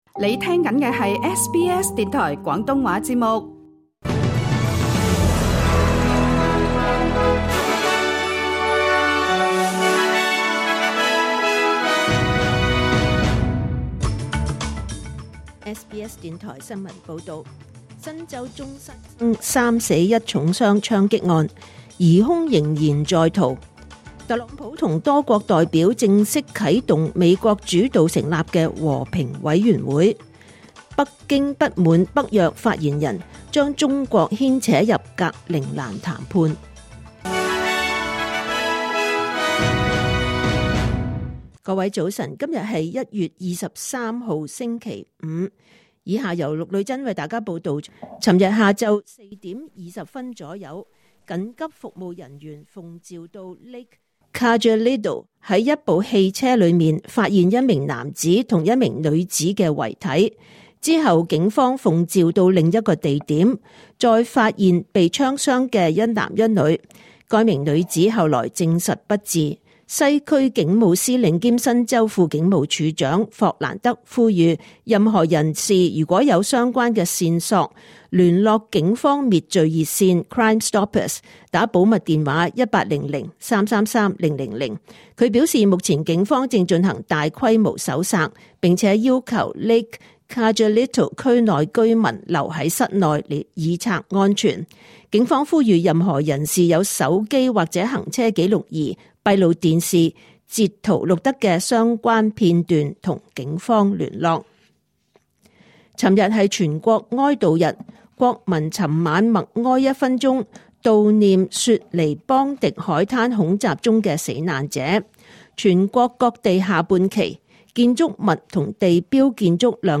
2026年1月23日SBS廣東話節目九點半新聞報道。